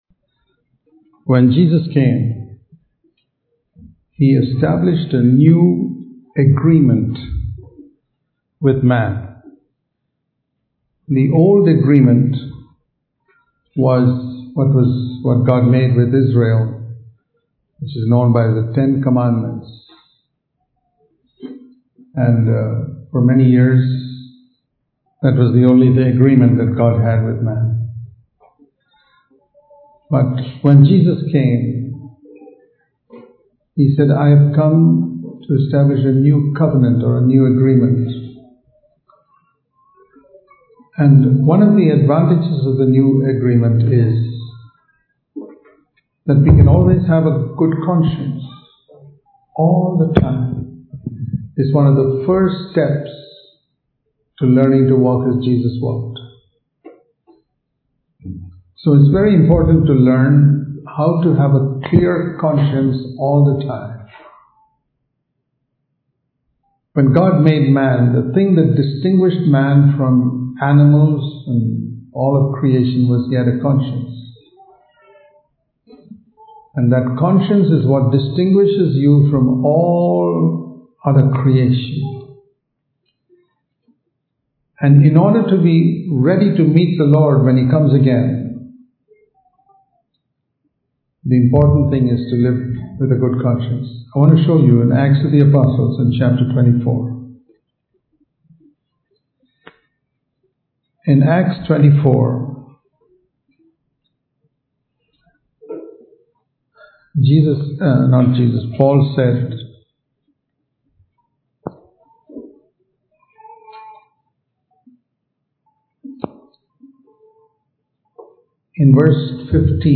The Importance Of A Good Conscience Dubai Special Meetings 2018
Sermons